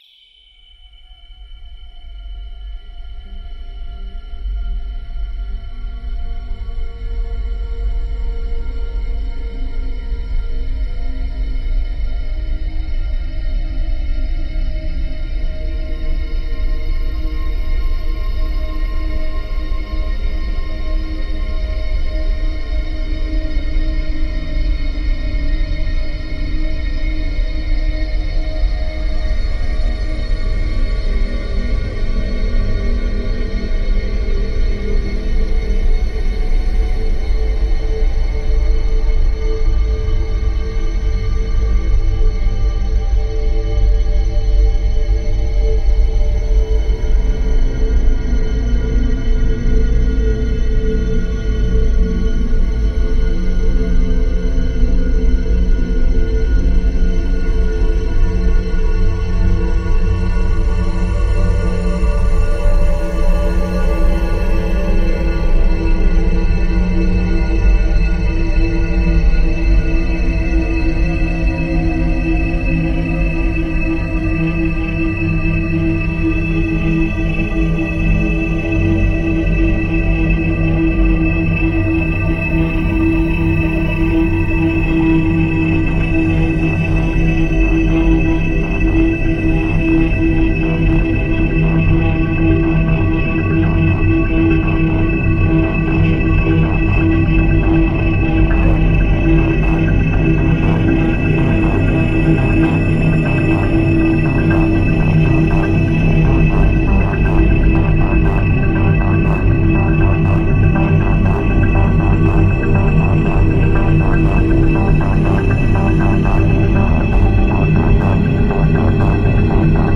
Reimagined sound of an escalator in Gatwick Airport, London